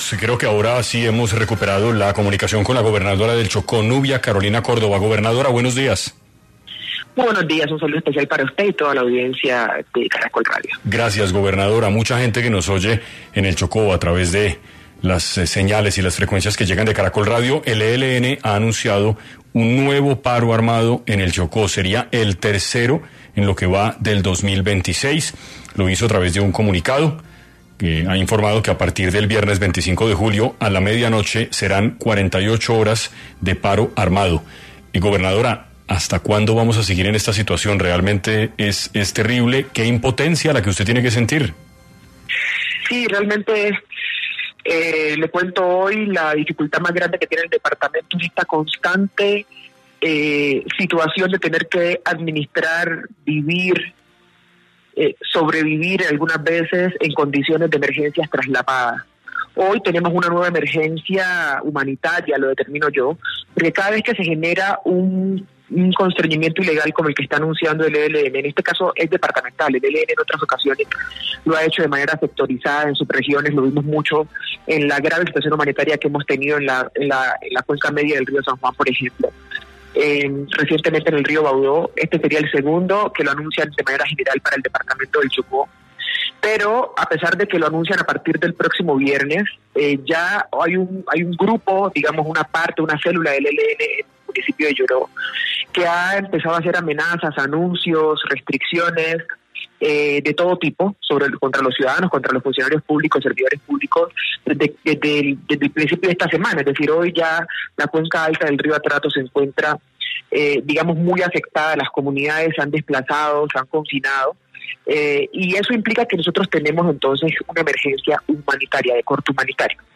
En este orden de ideas, la gobernadora del Chocó, Nubia Carolina Córdoba, pasó por los micrófonos de 6AM para abordar este anuncio del ELN.